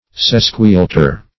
Sesquialter \Ses`qui*al"ter\, a.